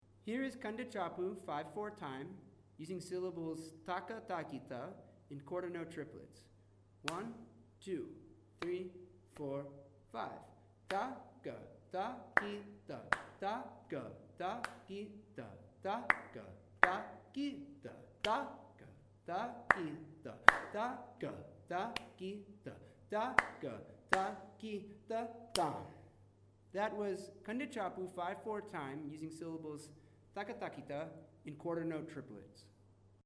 It is shown through a series of claps: Clap on beat 1; Clap on beat 3; Clap on beat 4.
Each clip shows Kanda Chapu thalum while reciting Ta Ka Ta Ki Ta in different subdivisions.
Kanda Chapu with Ta Ka Ta Ki Ta in Quarter Note Triplets
kandachapu-quarternotetriplets.mp3